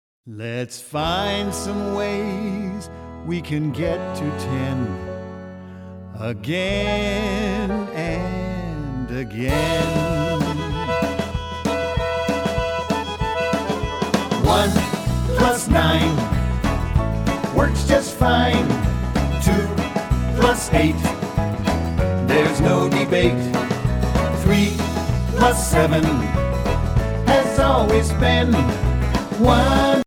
- Mp3 Vocal Song Track